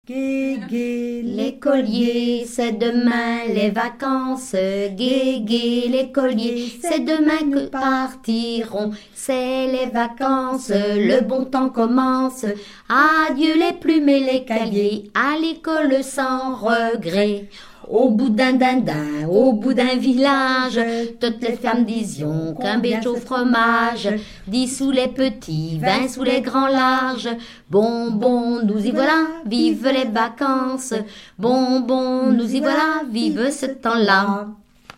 enfantine : lettrée d'école
Genre strophique
Pièce musicale inédite